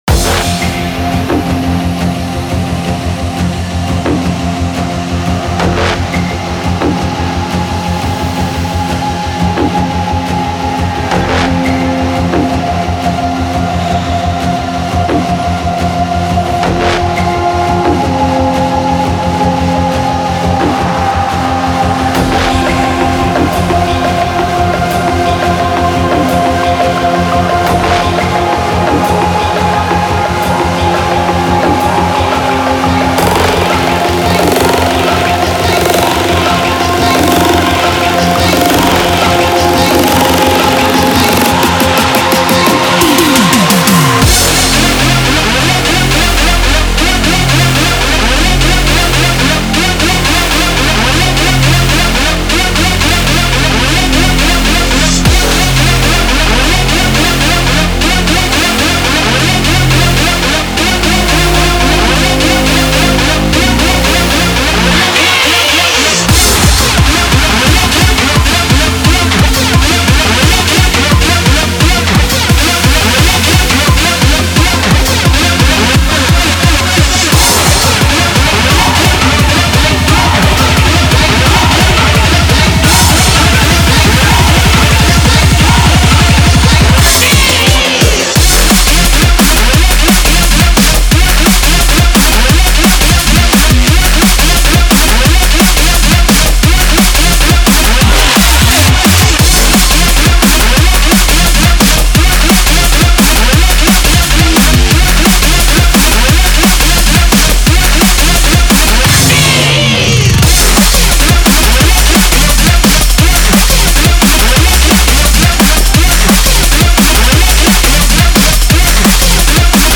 это динамичная композиция в жанре драм-н-бейс